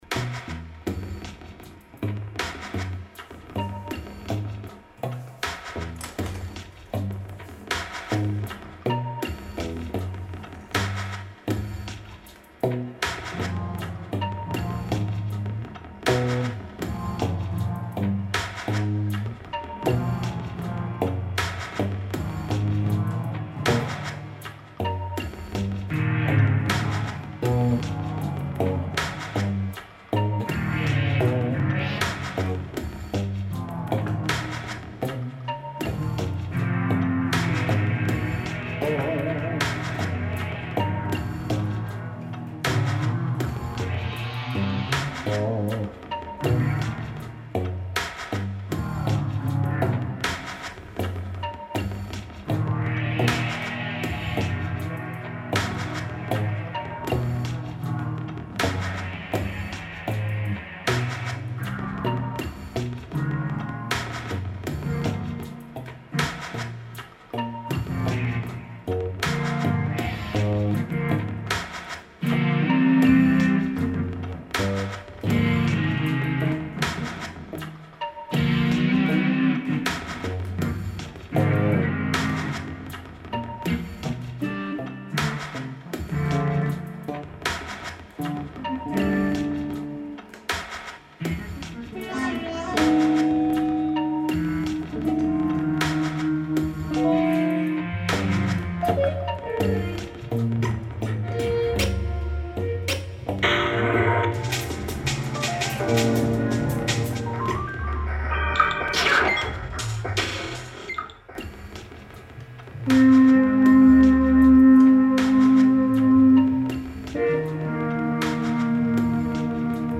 guitar
samples, K5000S